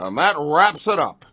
gutterball-3/Gutterball 3/Commentators/Baxter/wack_thatwrapsitup.wav at main